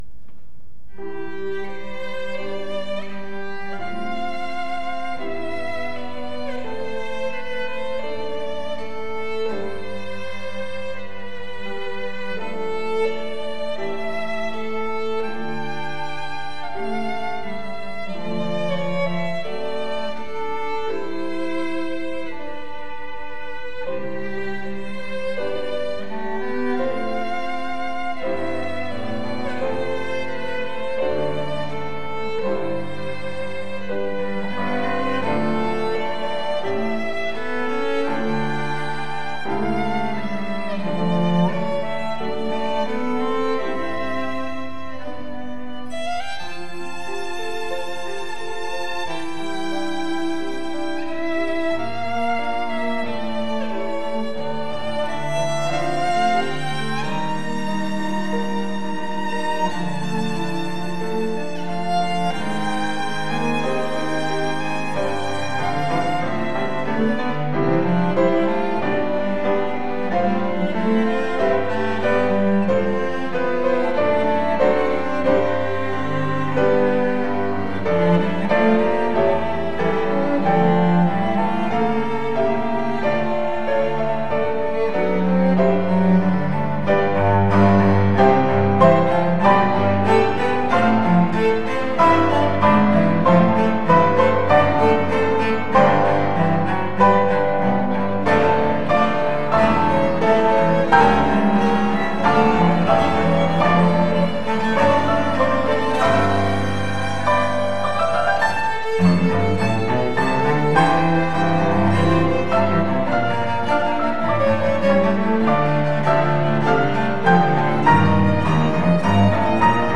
就是基本上每一代都有的那个 轻音乐 展开